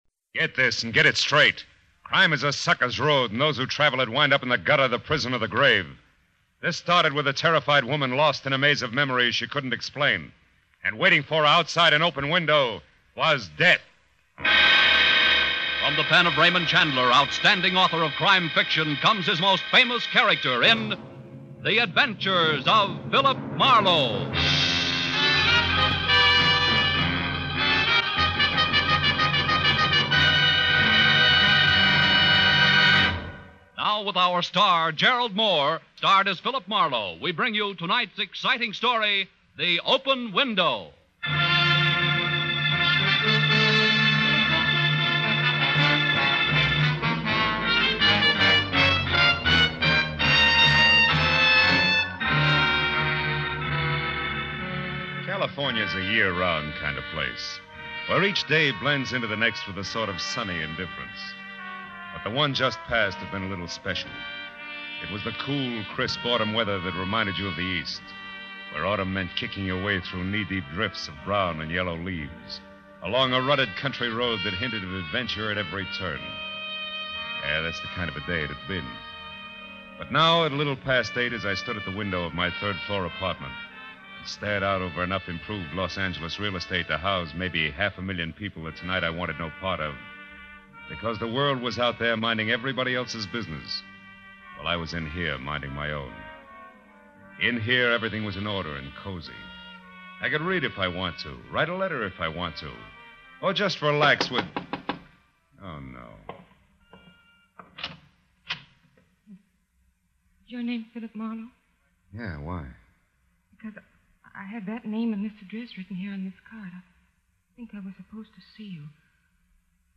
The program first aired 17 June 1947 on NBC radio under the title The New Adventures of Philip Marlowe, with Van Heflin playing Marlowe.
In 1948, the series moved to CBS, where it was called The Adventure of Philip Marlowe, with Gerald Mohr playing Marlowe.